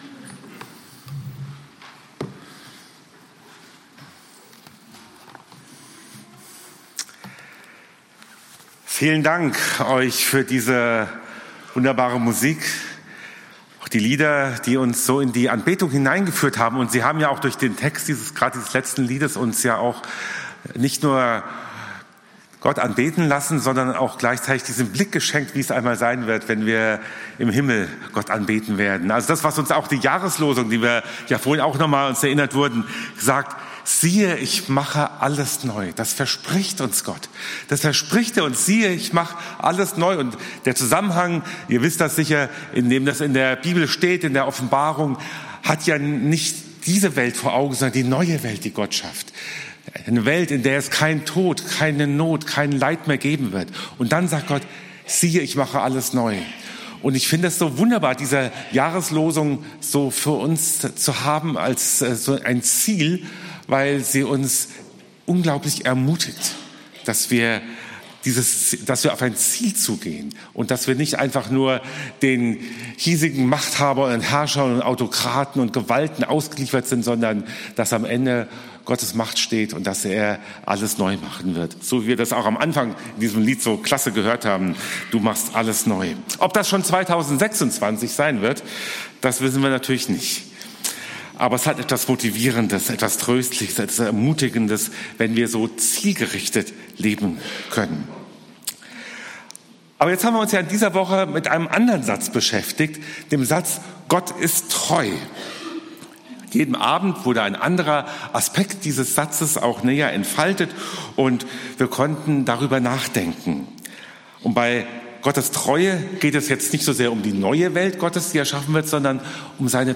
Unsere Botschaft für die Welt ~ EFG-Haiger Predigt-Podcast Podcast